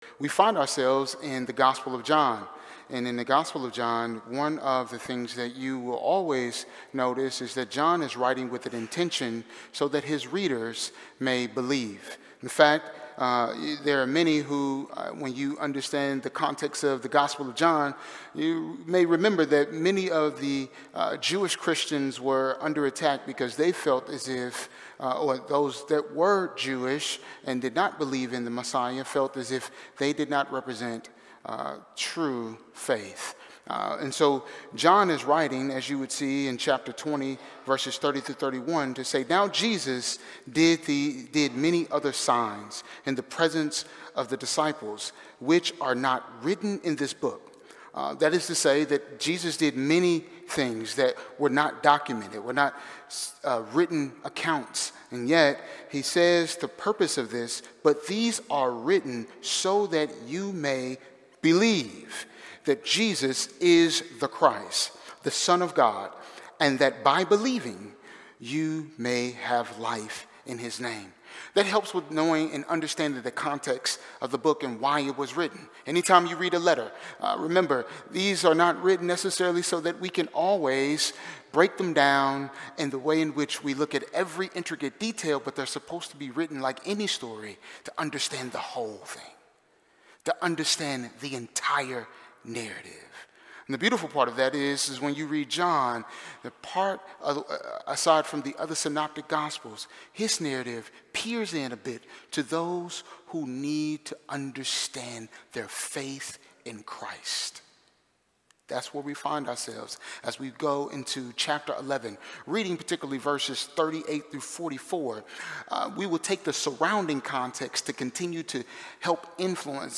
Standalone Sermon: The Crowd Had Hope and Belief